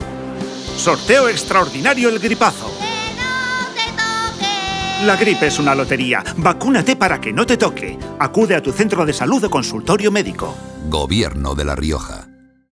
Elementos de campaña Cuñas radiofónicas Cuña radiofónica.